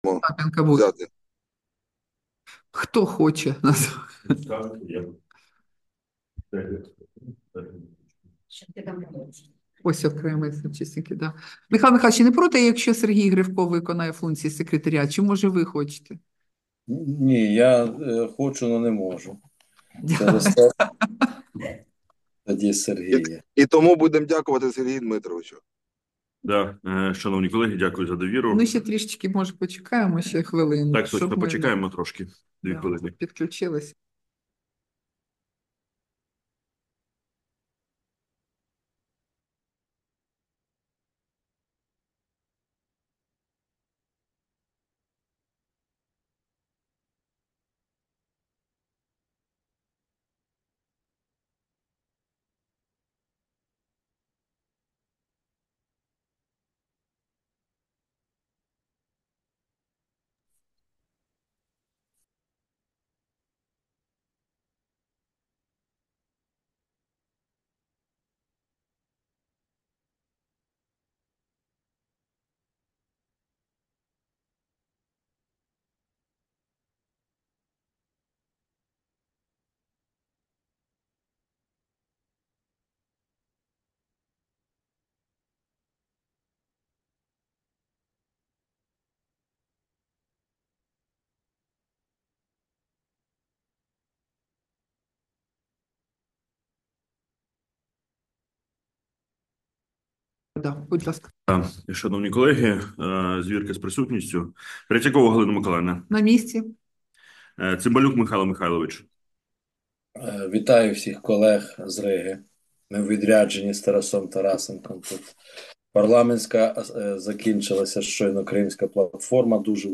Засідання Комітету від 24 жовтня 2024 року